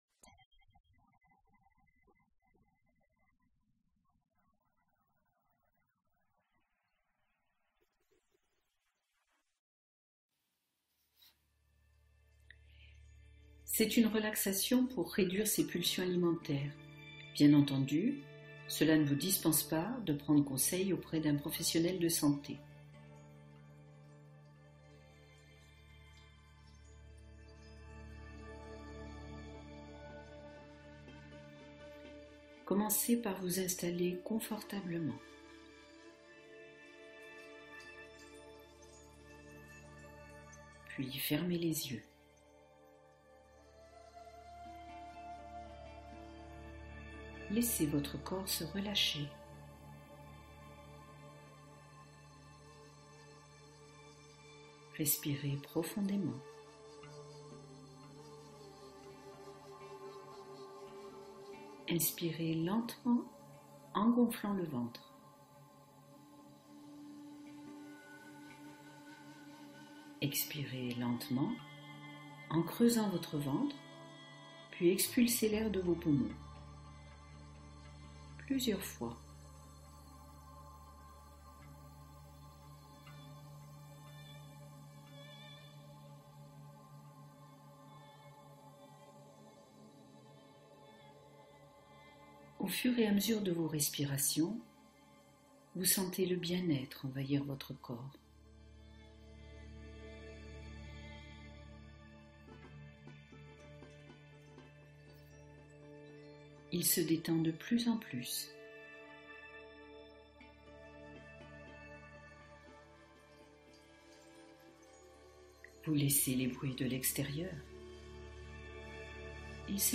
Prière du Soir Relaxation et confiance : apaiser et s’élever